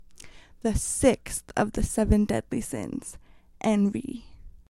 描述：女性，口语，七宗罪
Tag: 7 致命的 口语